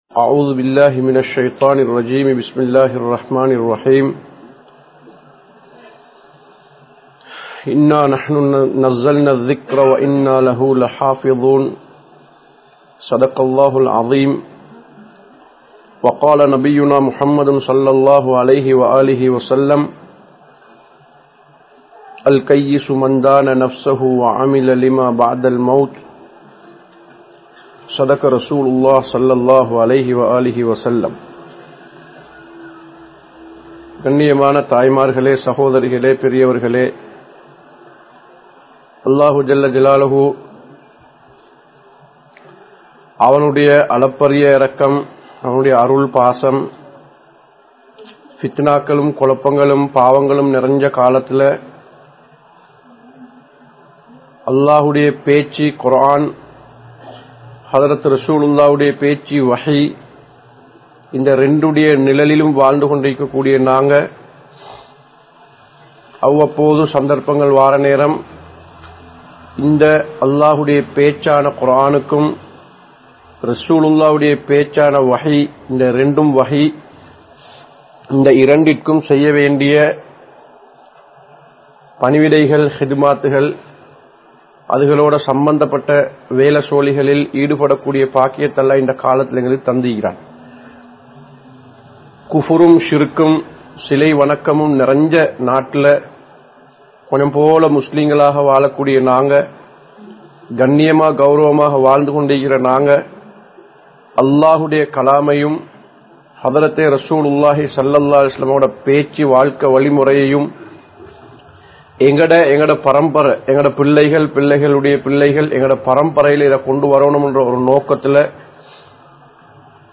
Kulanthai Valarpil Thaaien Pangalippu (பிள்ளை வளர்ப்பில் தாயின் பங்களிப்பு) | Audio Bayans | All Ceylon Muslim Youth Community | Addalaichenai
Darul Imaan Islamic Centre